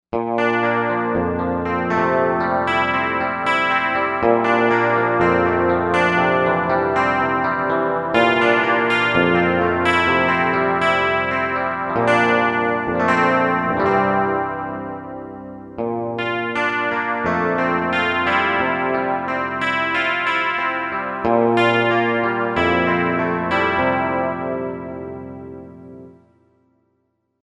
Synthesizer module
Korg M1Rex factory patches mp3 audio demos
78 Chorus Gtr
78 Chorus Gtr.mp3